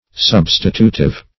substitutive - definition of substitutive - synonyms, pronunciation, spelling from Free Dictionary
Search Result for " substitutive" : The Collaborative International Dictionary of English v.0.48: Substitutive \Sub"sti*tu`tive\, a. [Cf. F. substitutif, L. substitutivus conditional.]